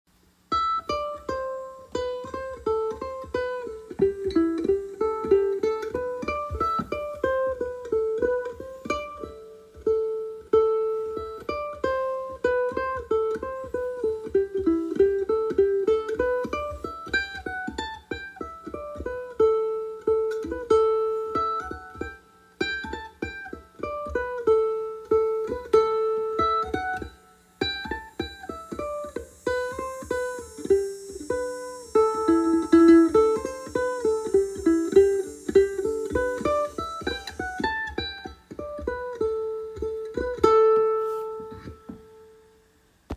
Key: Am
Form: Hornpipe
Played slowly for learning
M:4/4
Genre/Style: Irish hornpipe